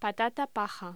Locución: Patata paja
voz